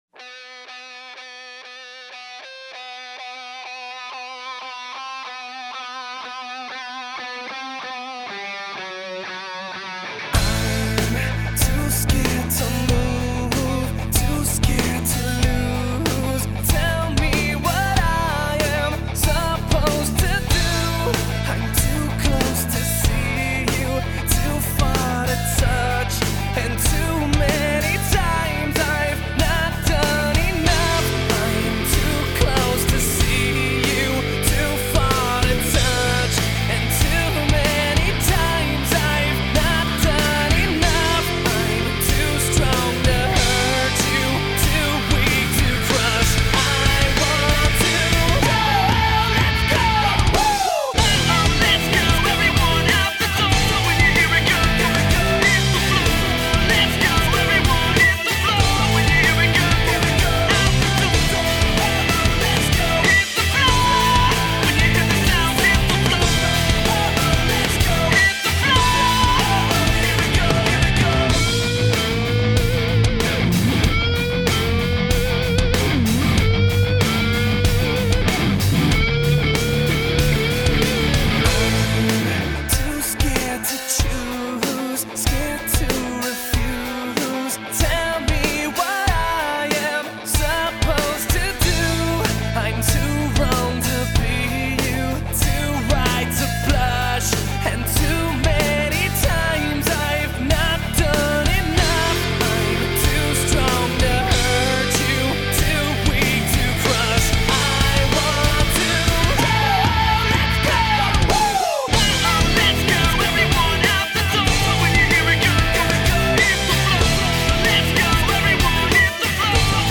Hardrock